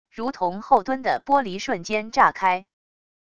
如同厚敦的玻璃瞬间炸开wav音频